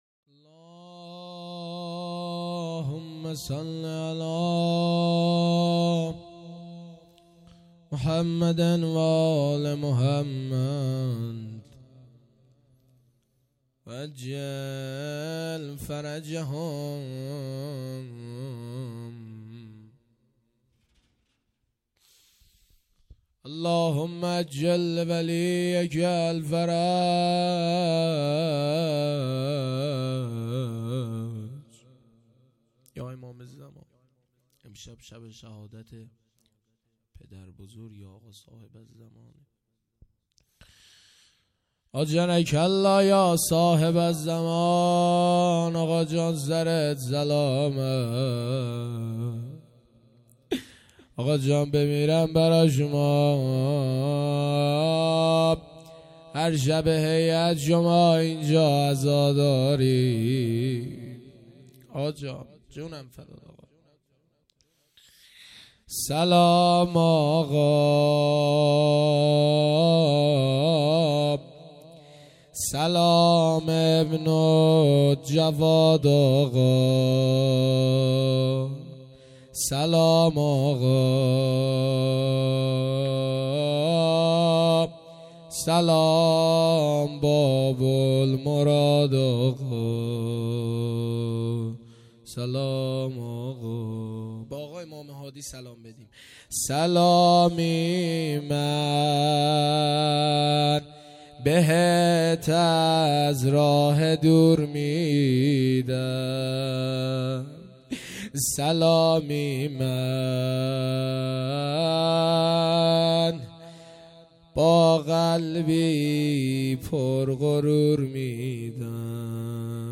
روضه
شب شهادت امام هادی(ع) - یکشنبه ۲۶ بهمن ماه ۹۹